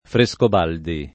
Frescobaldi [ fre S kob # ldi ]